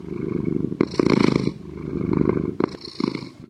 MinecraftConsoles / Minecraft.Client / Windows64Media / Sound / Minecraft / mob / cat / purr3.ogg
purr3.ogg